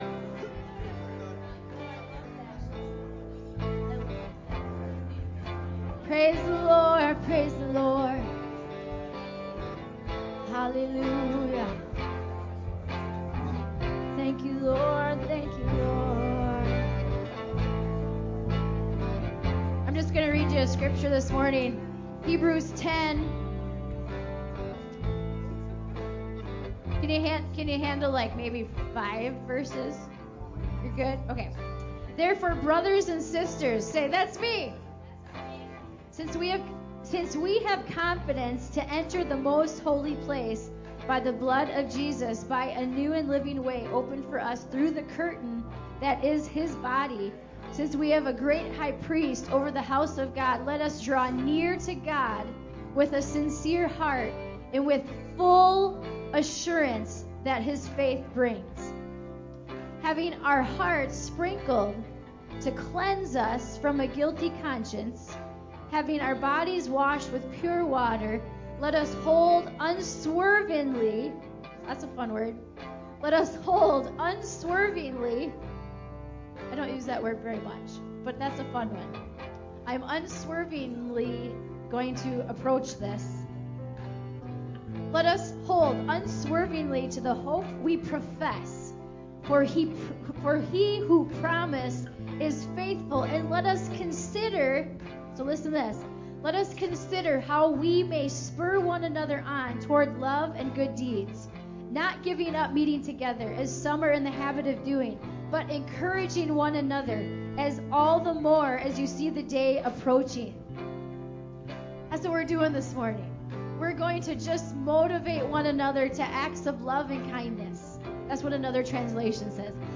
LISTEN (church service)